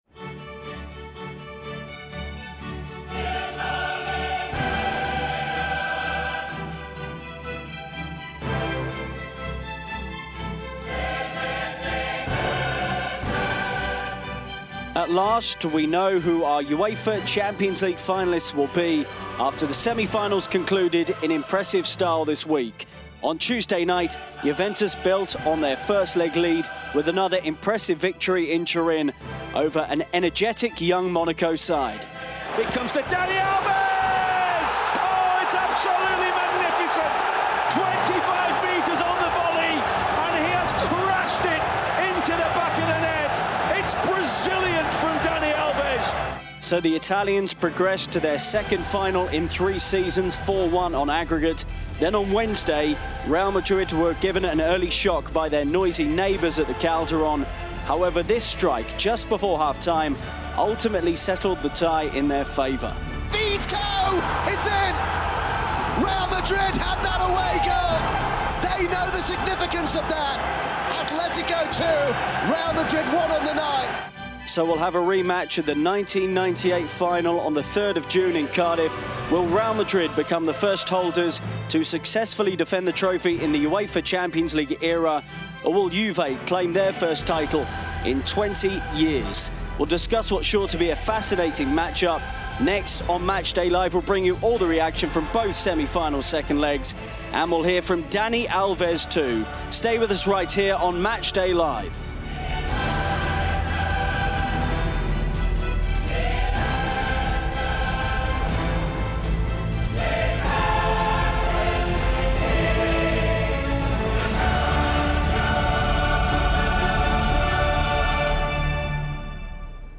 We also hear from Juventus's Dani Alves after his side made the final on Tuesday.